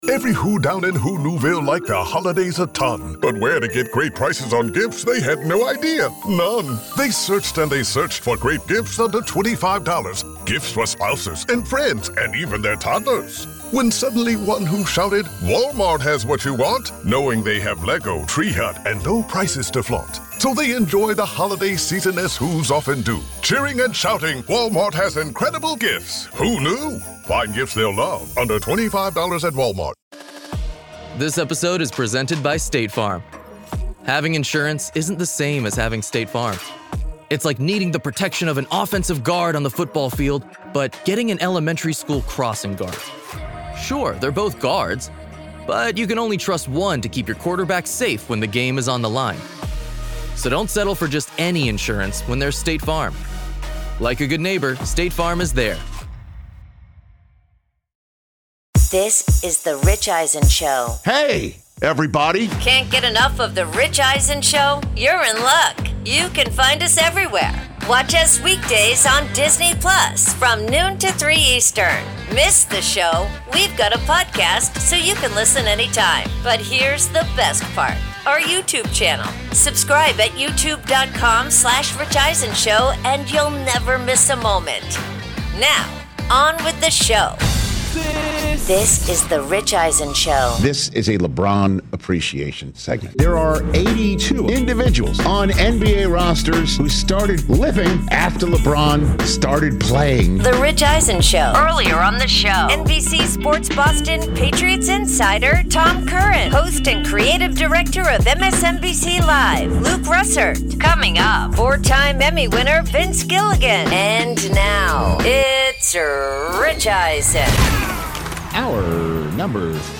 Hour 3: ‘Higher Register’ for NFL Week 12, plus ‘Breaking Bad/Pluribus’ Creator Vince Gilligan In-Studio